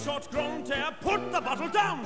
his voice is so commanding